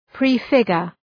Προφορά
{,pri:’fıgər}